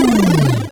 Death02.wav